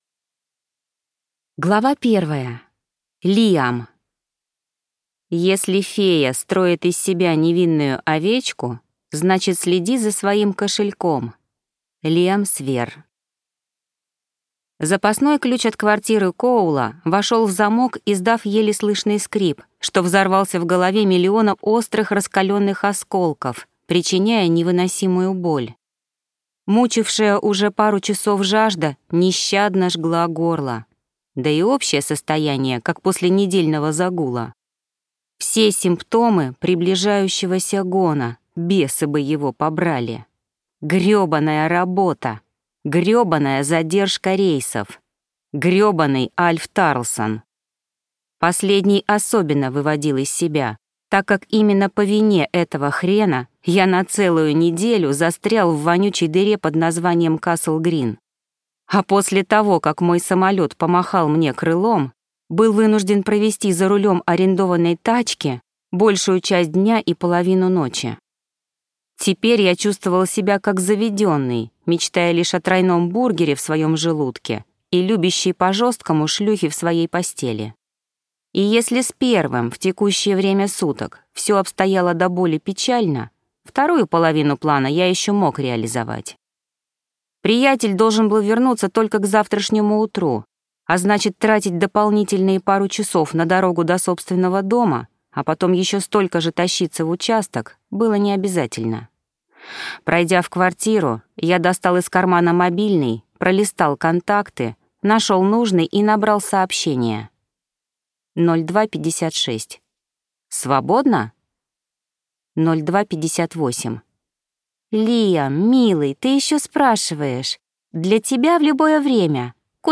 Аудиокнига Волк | Библиотека аудиокниг